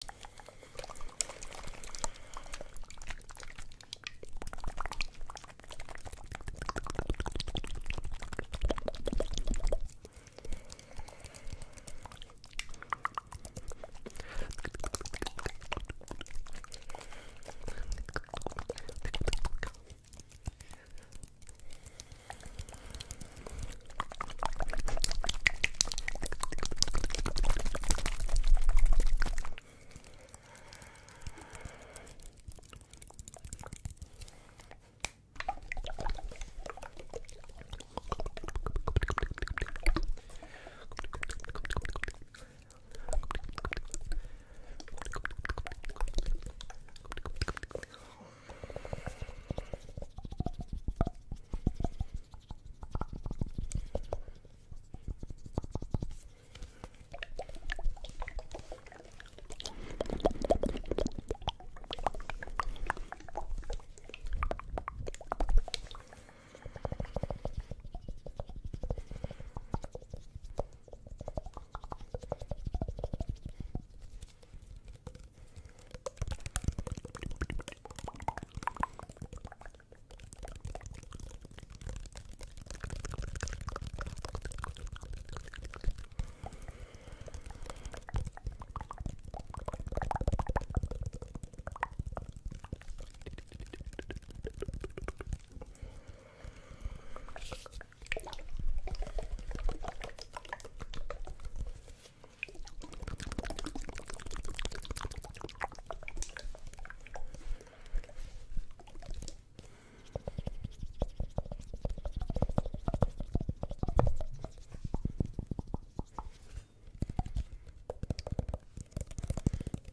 Fast and aggressive asmr